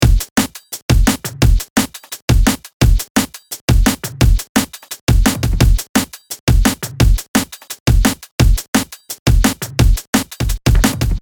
Rock N Phace Drum Loop 172Bpm
描述：Inspired by the Rockwell and Phace track "NO" This loop is filled plenty of grooving rhythms for your Drum Bass track.
标签： 172 bpm Drum And Bass Loops Drum Loops 1.88 MB wav Key : Unknown
声道立体声